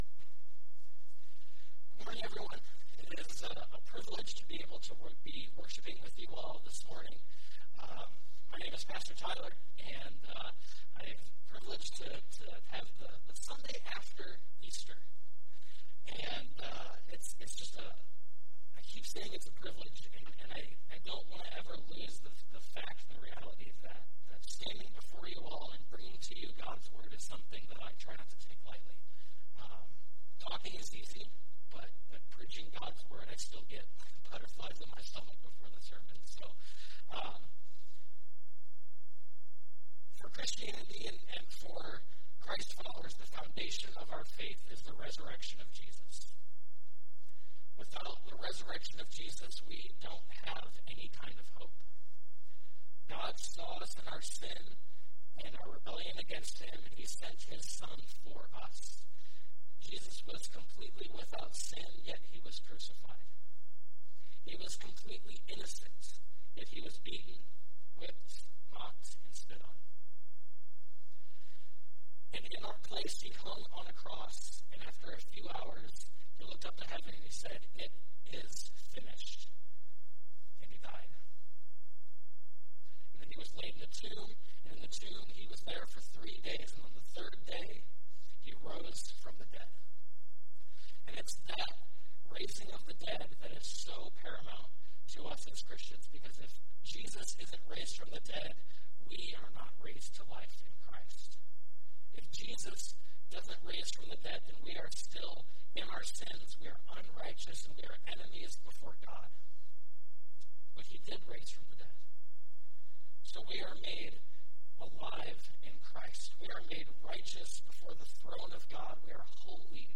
Stone Ridge Community Church Sermon Audio Library